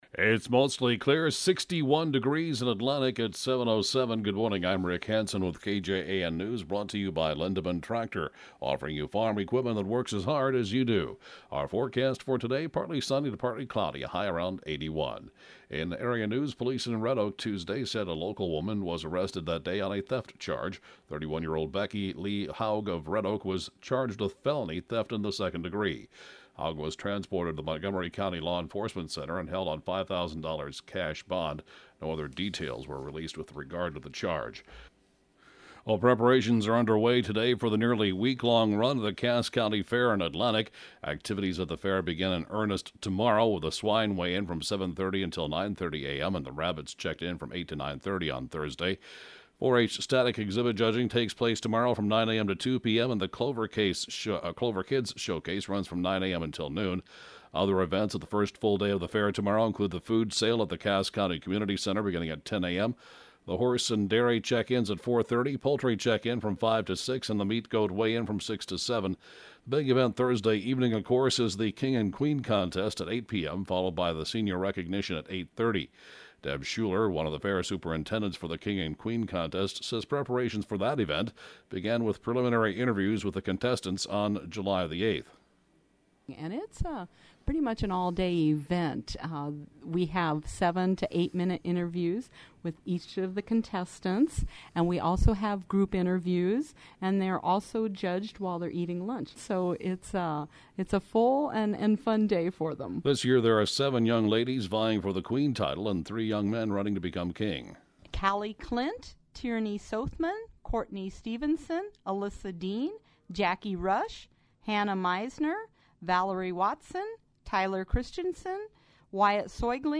(Podcast) 7:07-a.m. News and funeral report, 7/23/2014